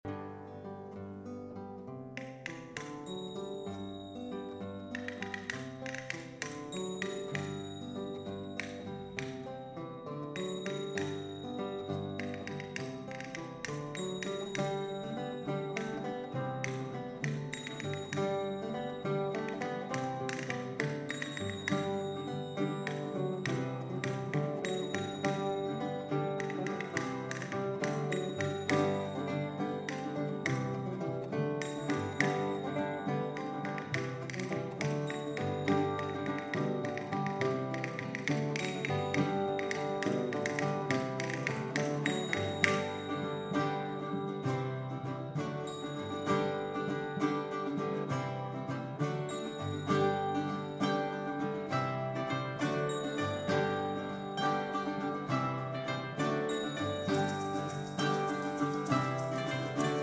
Baroque Ensemble
Baroque Violin, Baroque Viola
Violes de gambe
Percussion, Ud, Colascione, Psaltérion, Chalumeau
Guzheng
Theorbo, Baroque Guitar, Vihuela, Bol Chantant